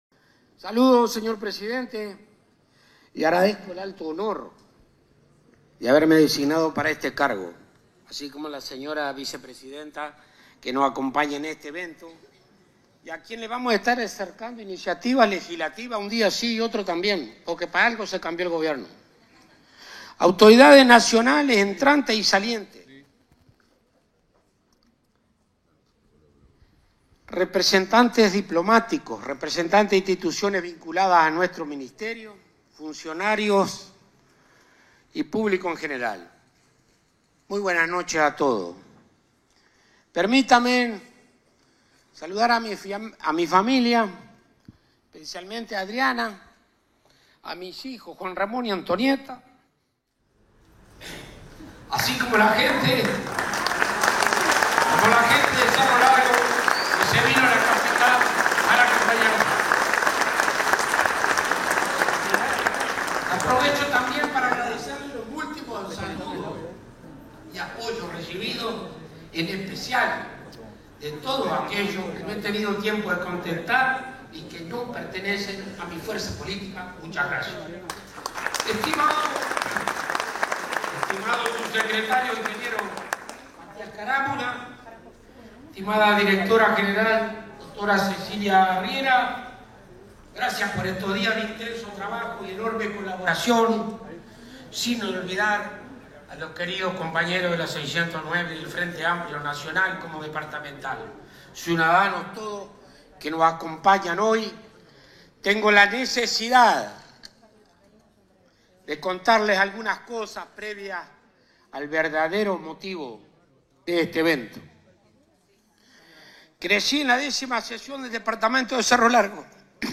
Ante la presencia del presidente de la República, profesor Yamandú Orsi, Fratti asumió su cargo este jueves 6, en la sede ministerial.